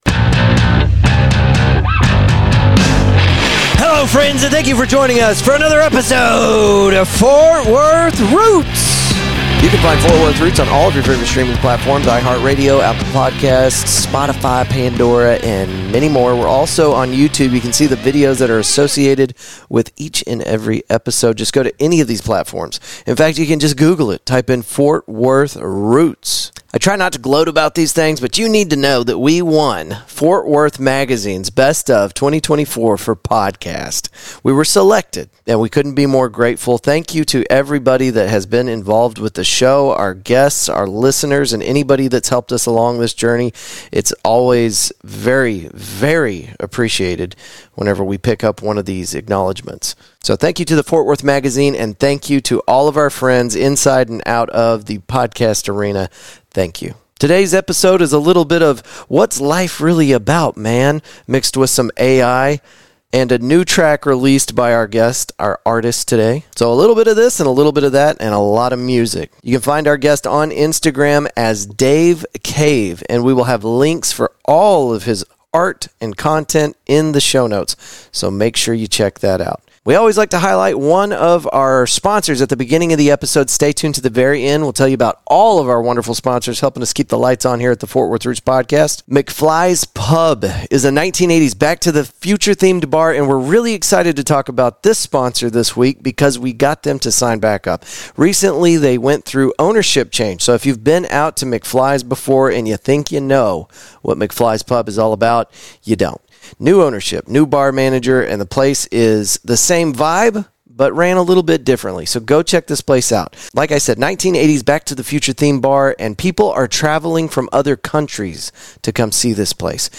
We play some of his tunes and some of our first stab at creating our own music during this recording.